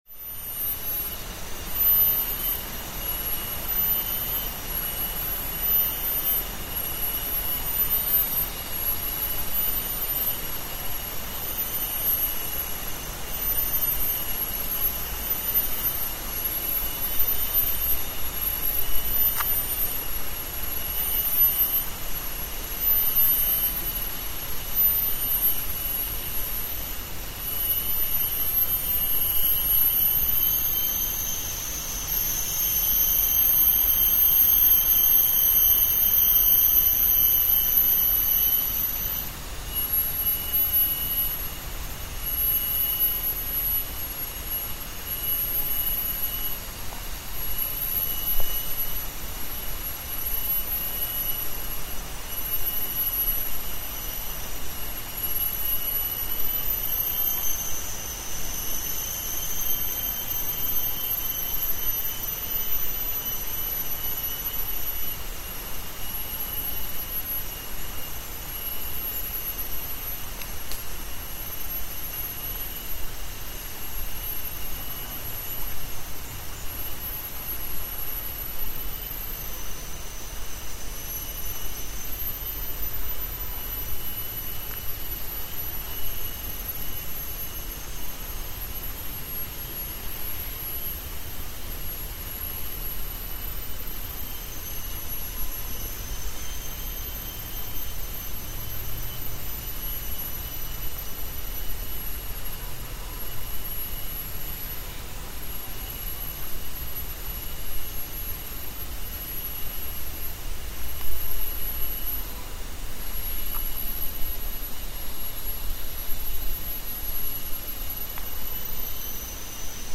Penang Botanic Gardens, the oldest of its kind in Penang Island, is the home for faunas such as cicadas and orioles. And they make music, too: the hypnotic drone by the insects is omnipresent while melodies by the birds are occasional.